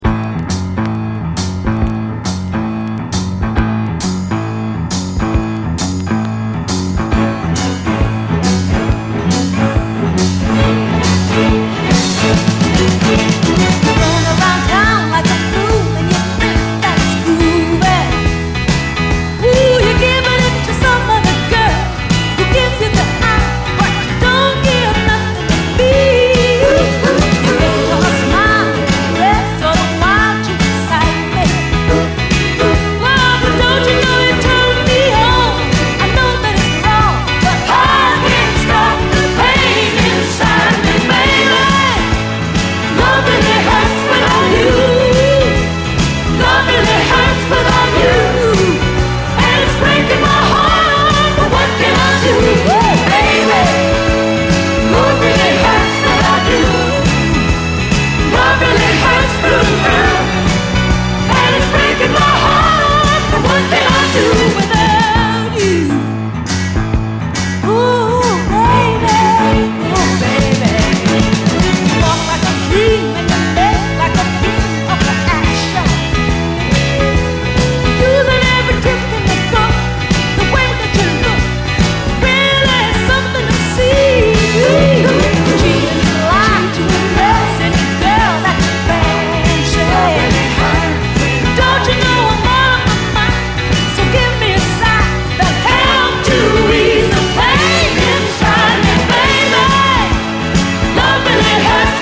トロピカルなブレイキン・ファンク
コク深くゴージャスなムードがタマラナイ。